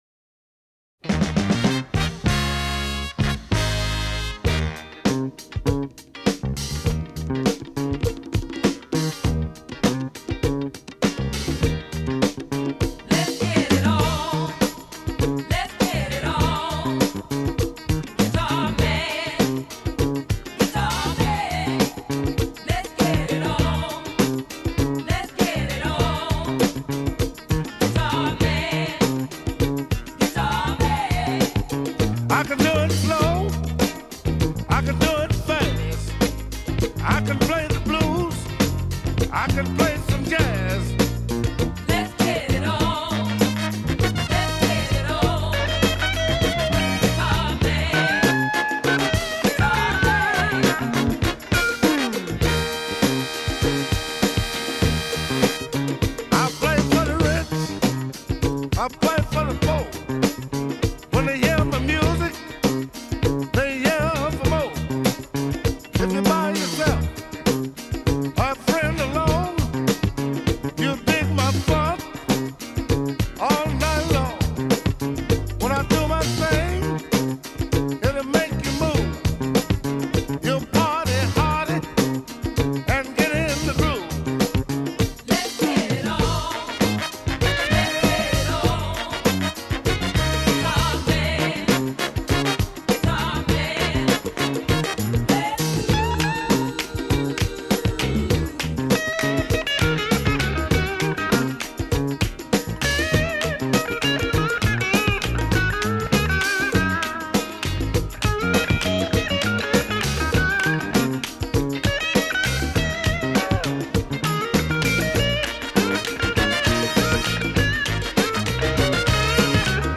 TEMPO :  99
Eb9
Trumpet Riff  [ Ebsus7]
Solo Guitare